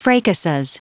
Prononciation du mot fracases en anglais (fichier audio)
Prononciation du mot : fracases